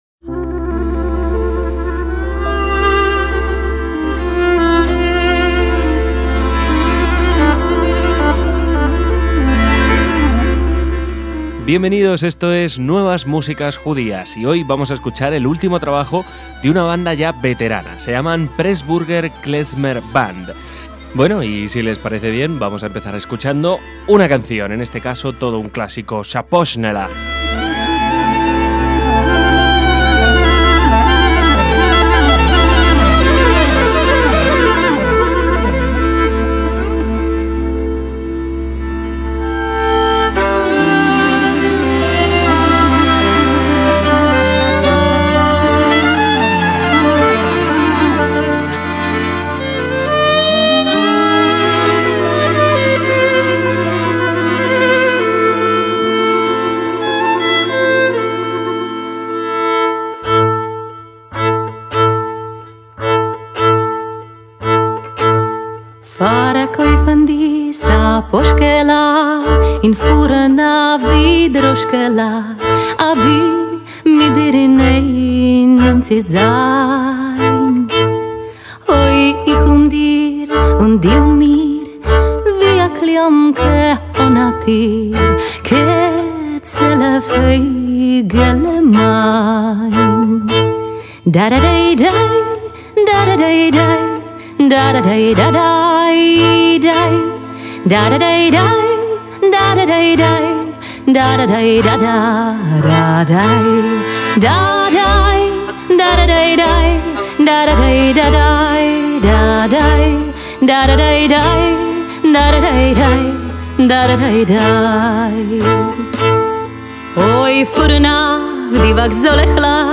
violín
contrabajo
clarinete
saxofón